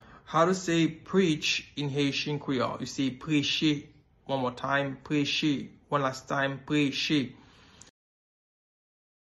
Pronunciation:
21.How-to-say-Preach-in-Haitian-Creole-–-Preche-pronunciation.mp3